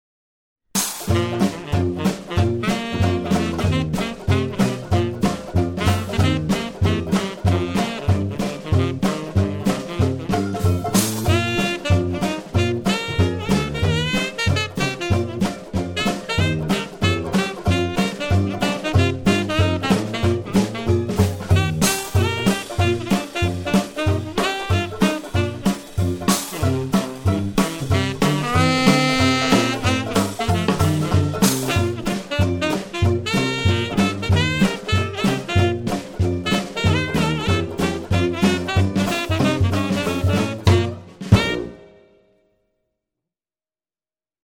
Instrumental Rat-Pack Jazz Band